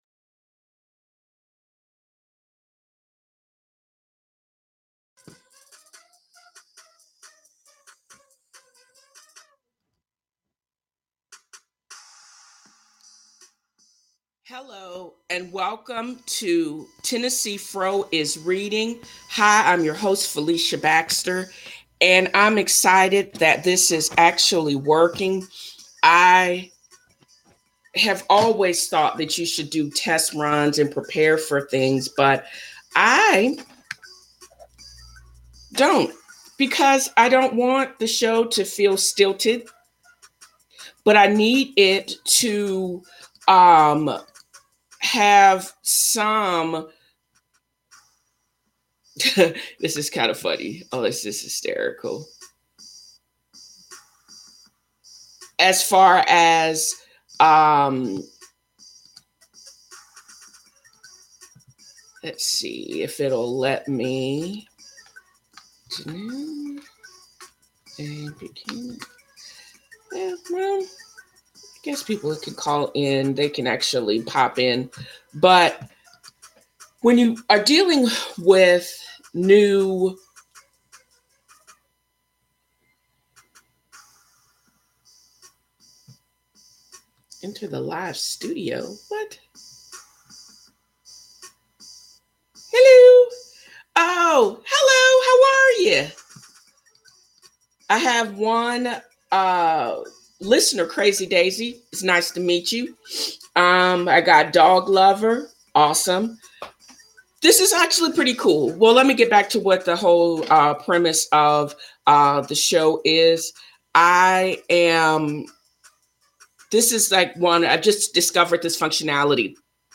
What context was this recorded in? TNFro Is Reading Podcast Live Event